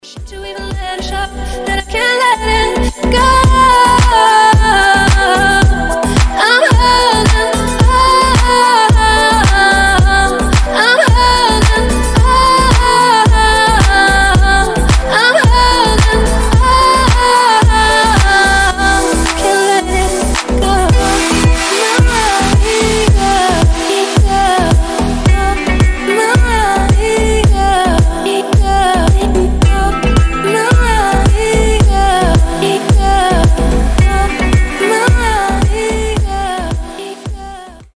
• Качество: 320, Stereo
гитара
deep house
мелодичные
восточные
красивый женский голос
Vocal House
Стиль: deep house